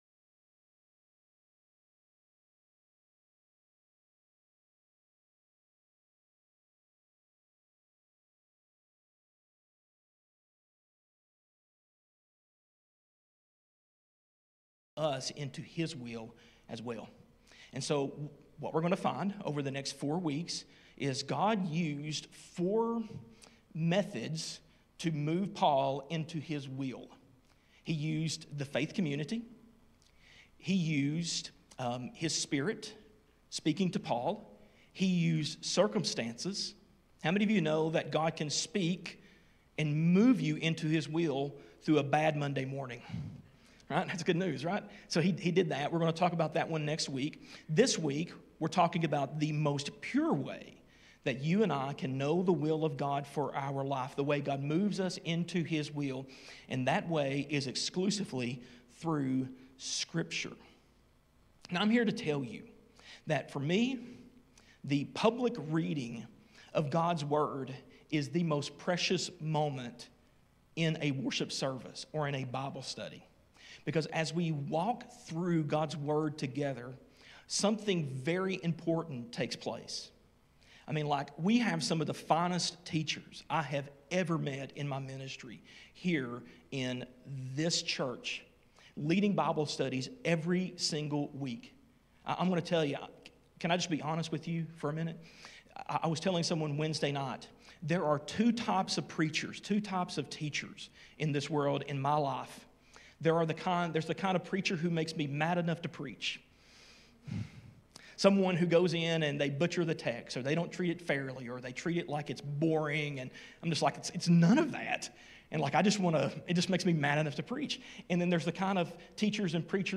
A message from the series "Trip To Troas."